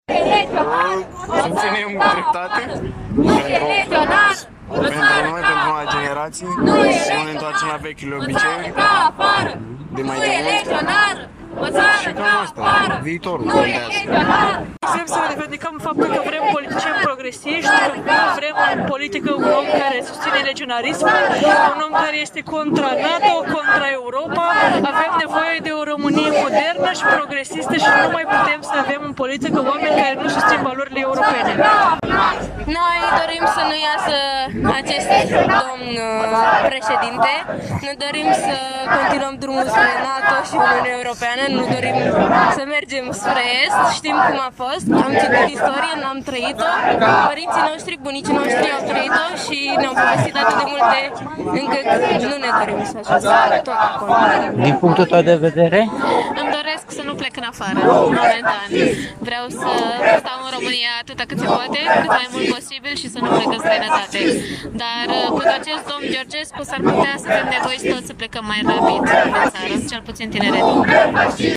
Aproximativ 300 de tineri au protestat, marți seară, în Piața Unirii din Iași împotriva lui Călin Georgescu, candidatul independent care a câştigat primul tur al alegerilor prezidenţiale.
26-nov-rdj-20-Vox-protest-Iasi.mp3